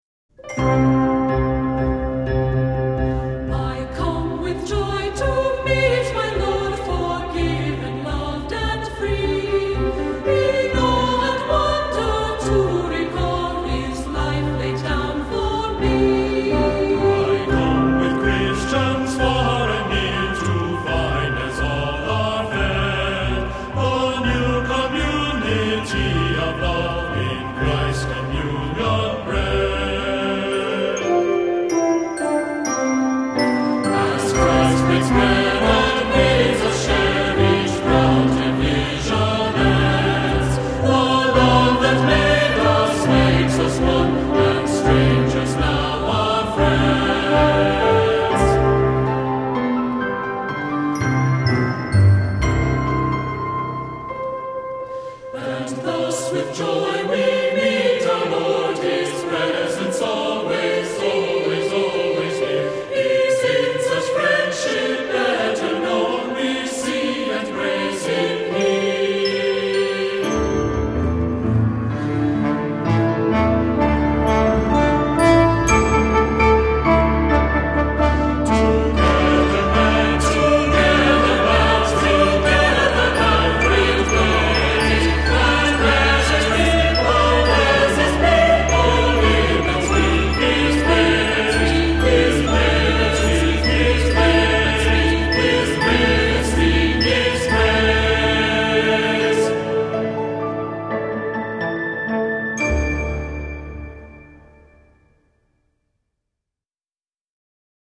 Voicing: Two-part equal; Two-part mixed